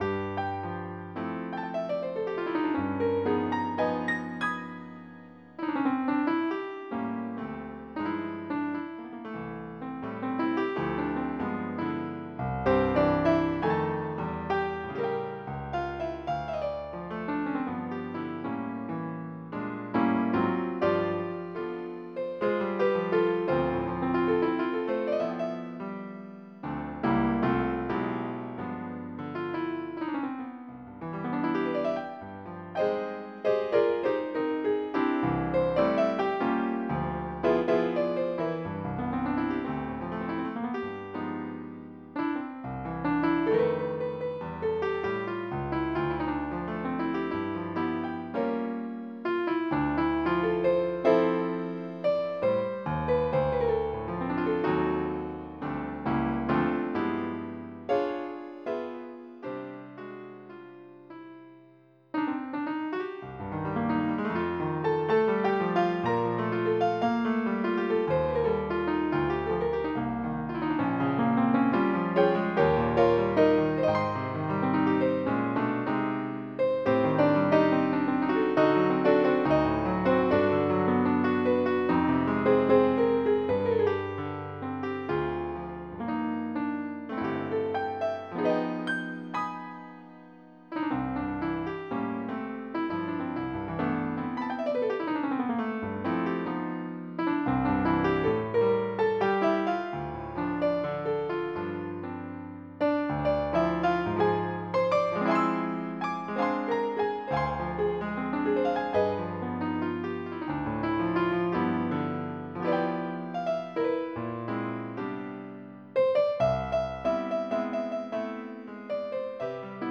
MIDI Music File
Type General MIDI
JAZZ08.mp3